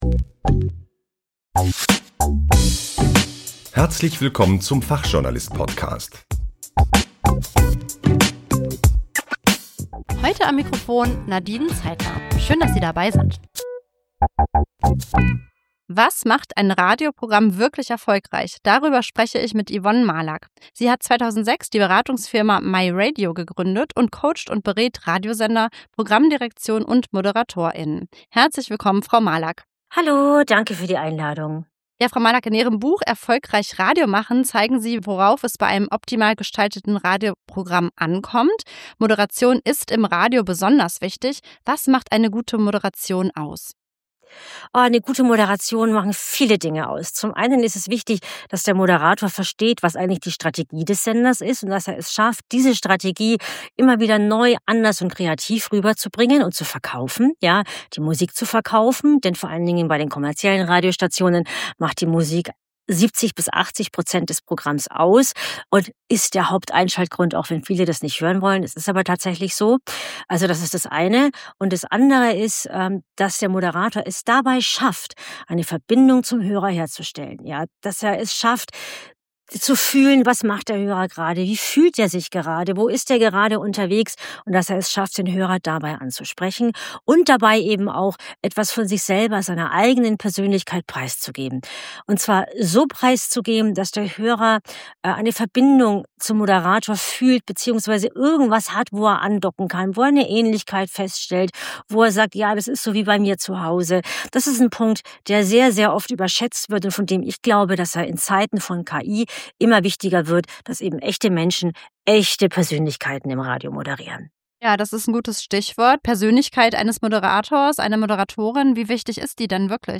Der Fachjournalist-Podcast ist ein gemeinsames Nachwuchsprojekt des Deutschen Fachjournalisten-Verbands (DFJV) und des Deutschen Journalistenkollegs. Journalistenkollegschüler und junge Journalisten machen hier qualitativen Fachjournalismus hörbar.